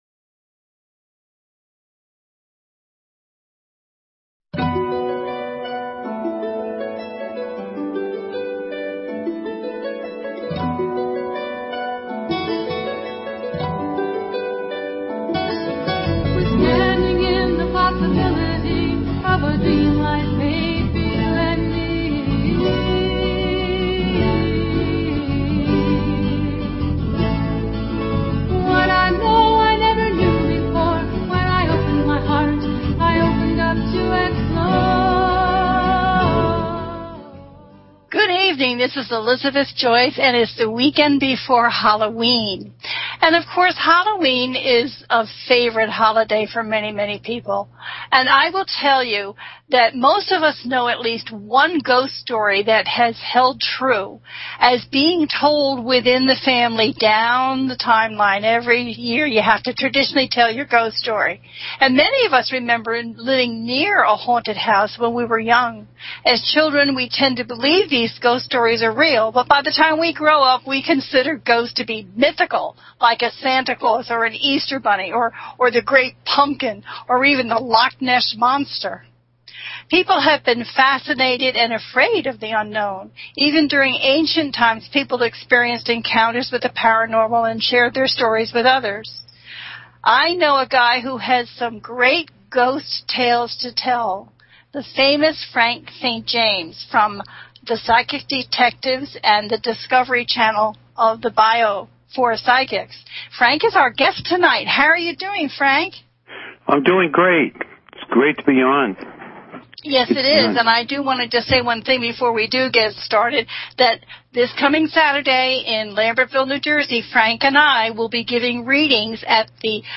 This is a call in show, so have your first name, birthday, and focused question ready. The show's focuses on Halloween, it’s history, ghosts, goblins, and dressing in costume.